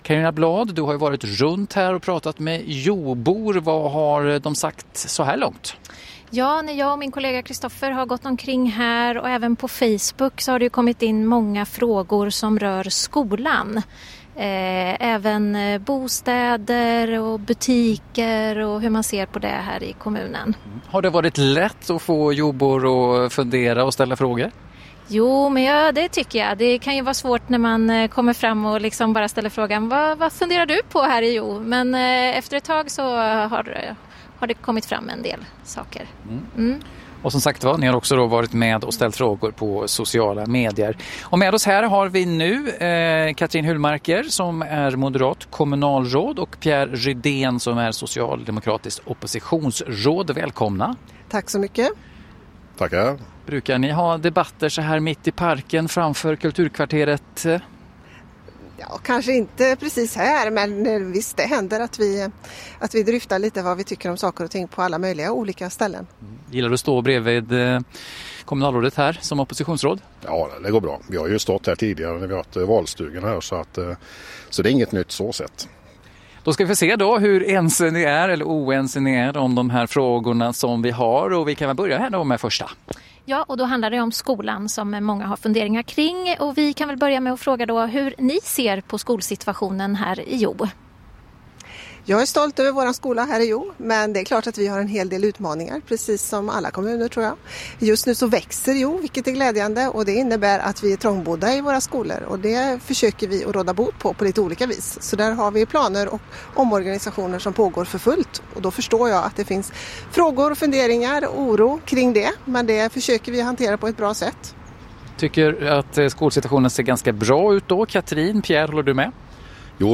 Där eftermiddagsprogrammet sändes från Kulturkvarteret.
Catrin Hulmarker (M) och oppositionsrådet Pierre Rydén (S) svarade på lyssnarnas frågor om Hjo.